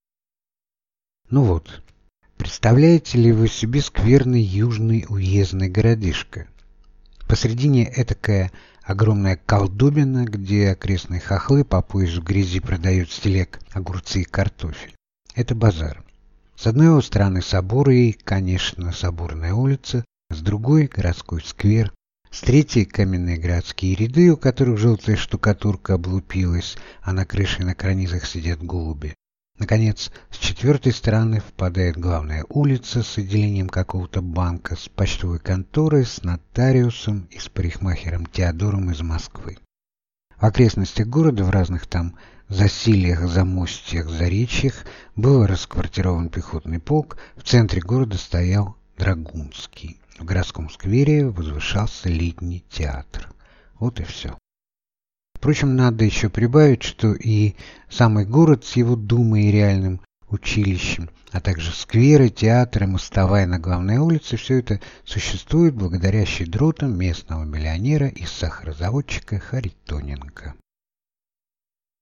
Аудиокнига Как я был актером | Библиотека аудиокниг